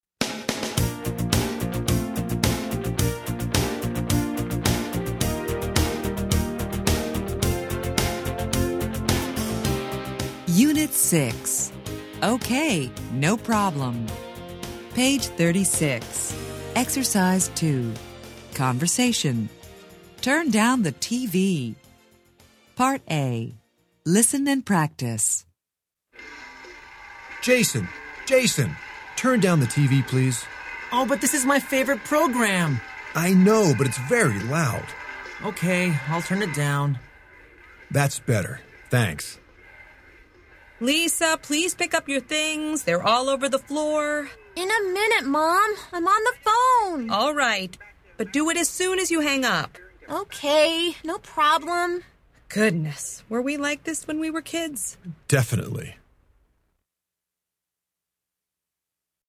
Interchange Third Edition Level 2 Unit 6 Ex 2 Conversation Track 11 Students Book Student Arcade Self Study Audio